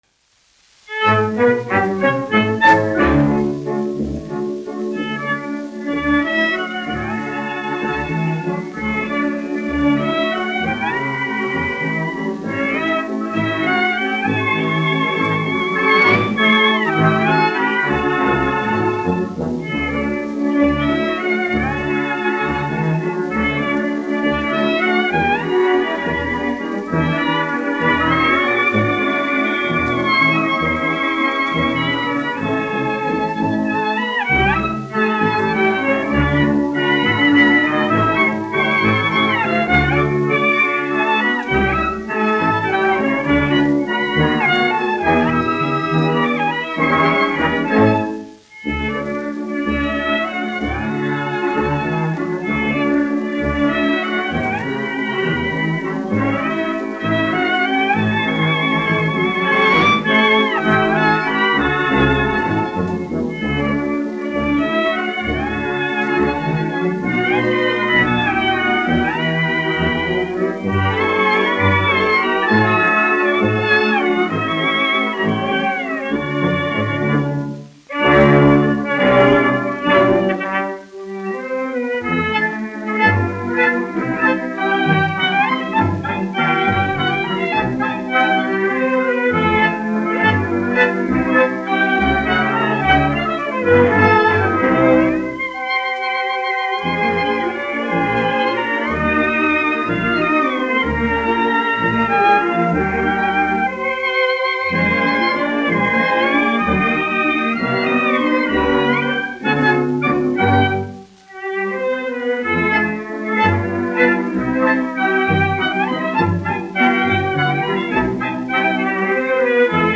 1 skpl. : analogs, 78 apgr/min, mono ; 25 cm
Valši
Latvijas vēsturiskie šellaka skaņuplašu ieraksti (Kolekcija)